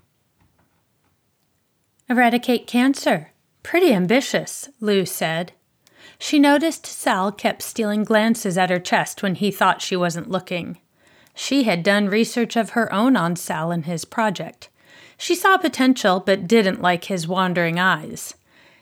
I applied AudioBook Mastering and the clip easily meets technical standards.
I applied DeEsser and the tonal balance seems to work better.
If I lean forward and really pay attention, I can just barely hear the room you’re recording in.